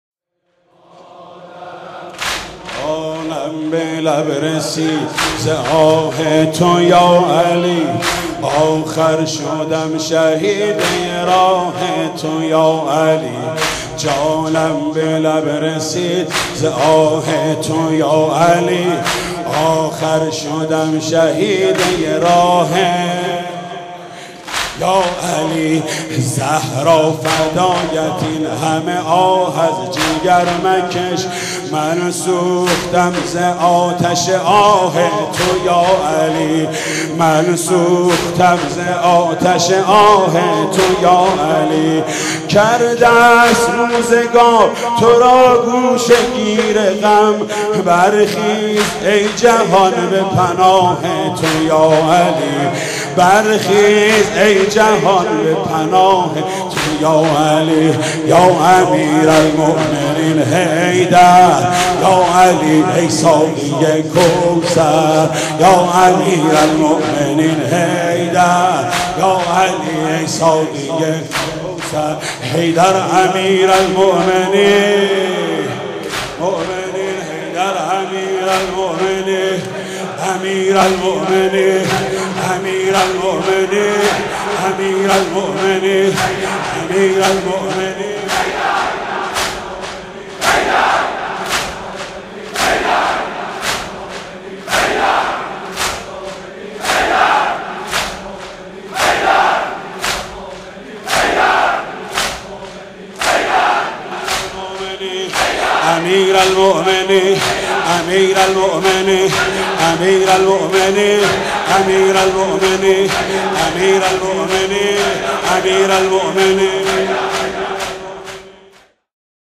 مداحی و مرثیه خوانی